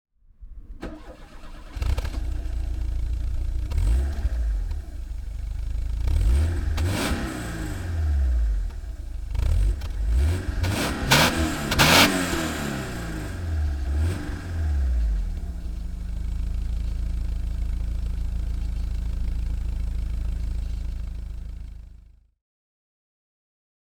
Alfa Romeo 33 1.7 i.e. 4x4 Elegante (1991) - Starting and idling